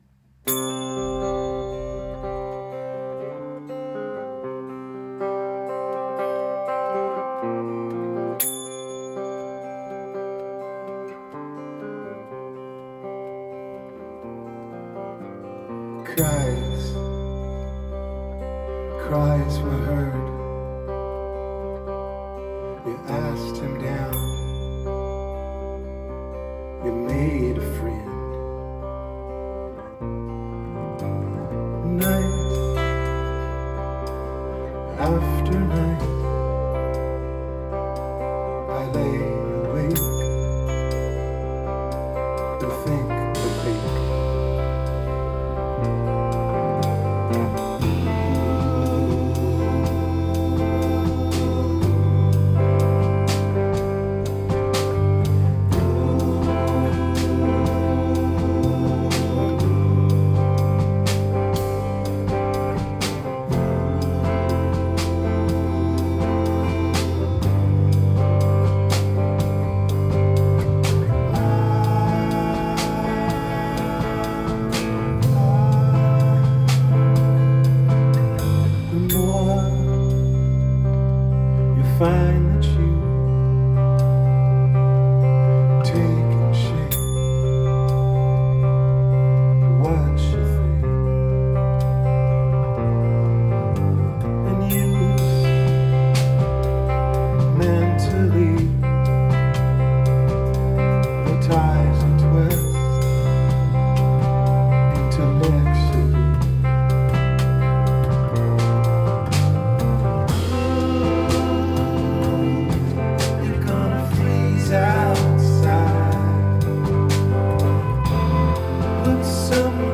Rehearsals 6.9.2013